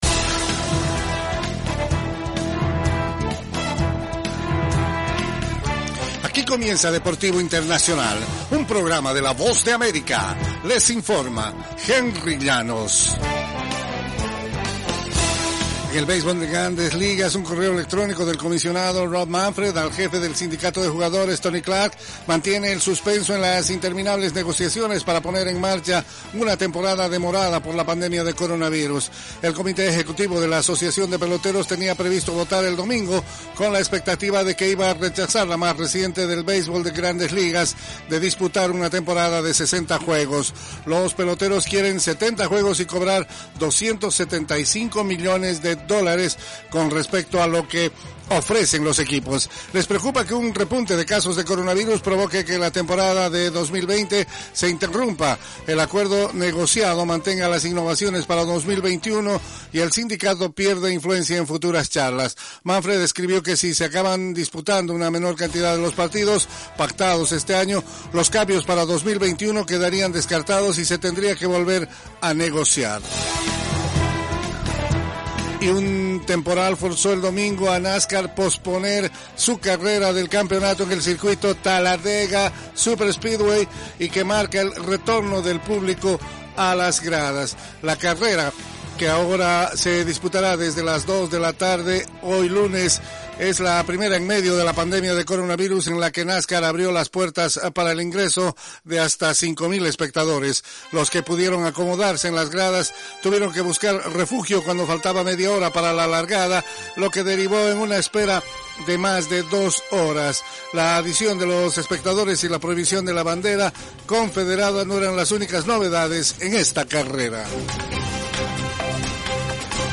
Las noticias deportivas llegan desde los estudios de la Voz de América en la voz de